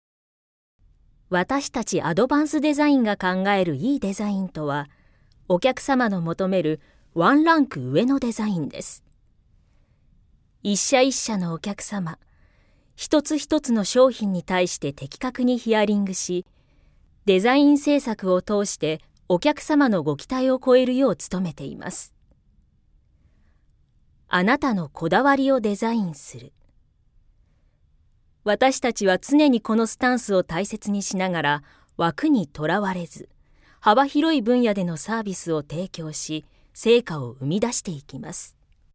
◆企業系◆